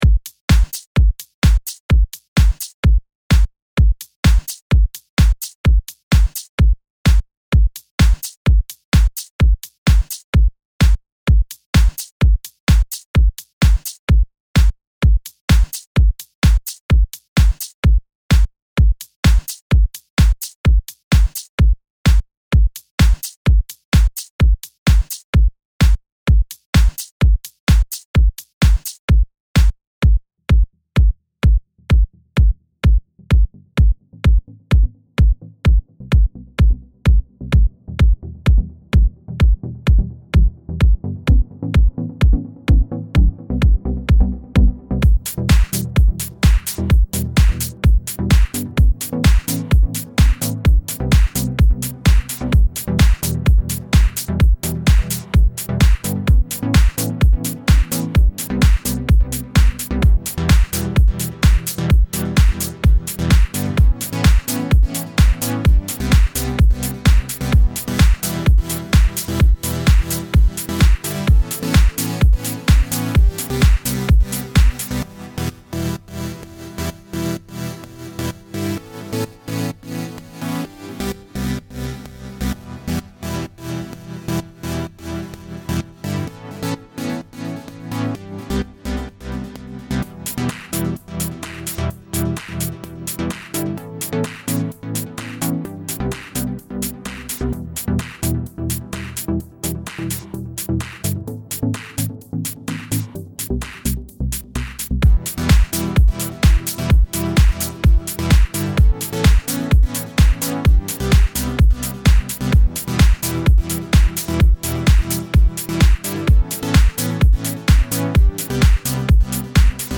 Hi, currently working on a track, Just wrote the chord progression for it and really need a vocalist, Have a listen and get back to me Keep in mind the volumes, mixing and mastering etc is at an early stage so dont be intimidated by the quality of the track.